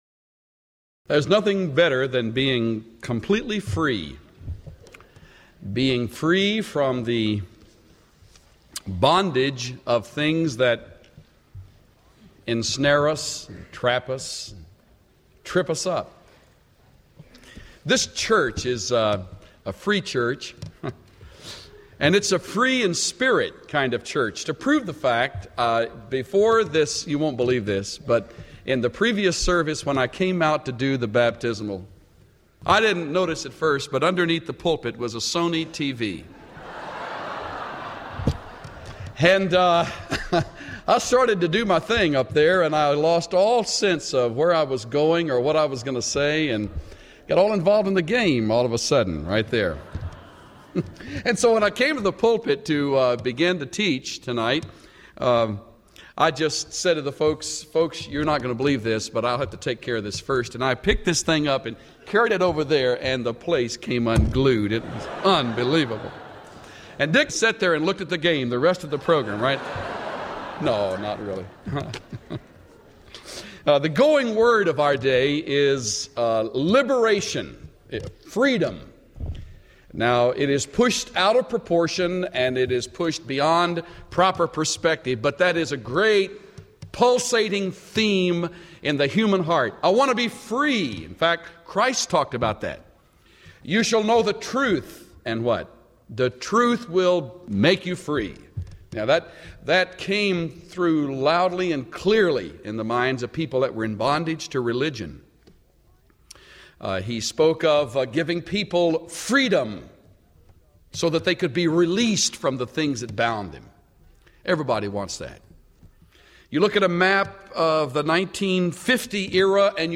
Listen to Chuck Swindoll’s overview of Exodus in his audio message from the Classic series God’s Masterwork.